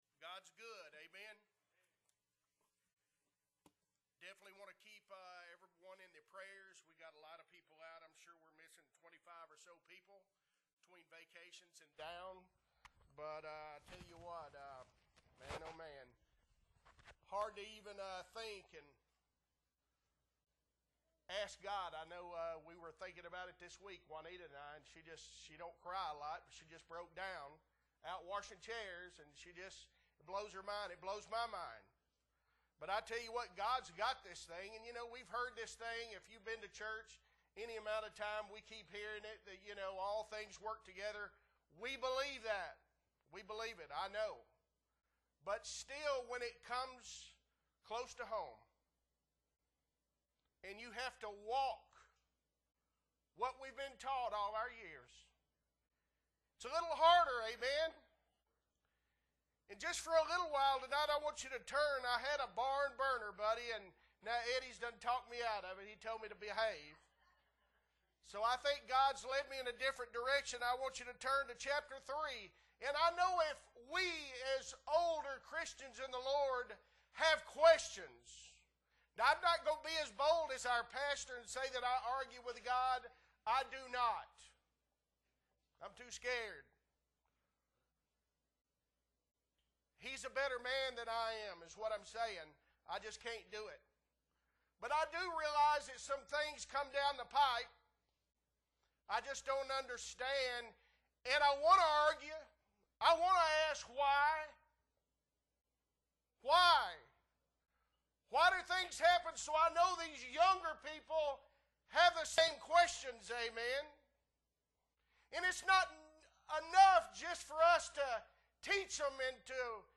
September 13, 2023 Wednesday Night Service - Appleby Baptist Church
Sermons